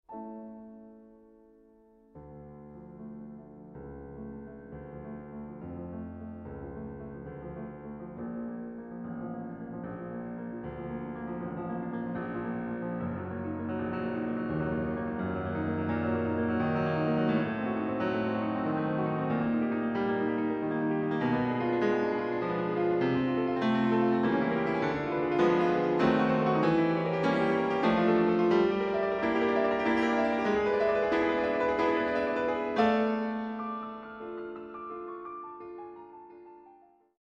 Piano
Trackdown Studios